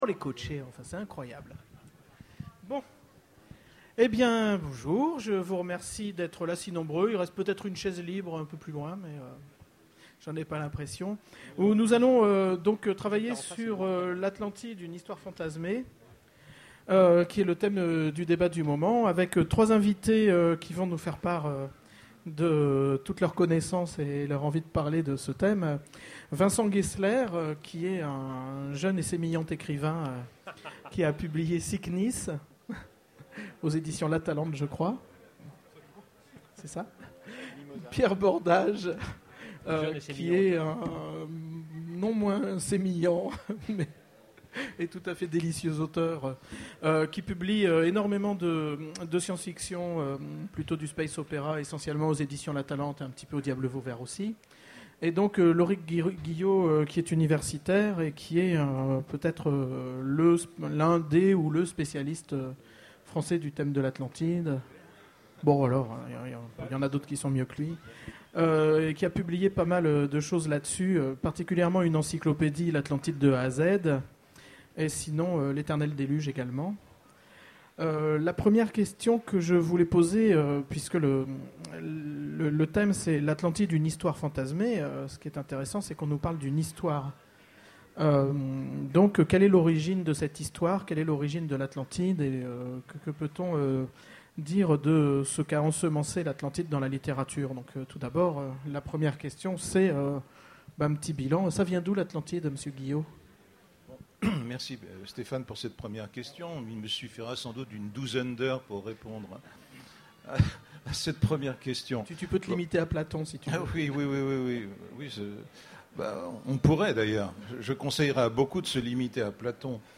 Utopiales 2011 : conférence l'Atlantide, une histoire fantasmée ?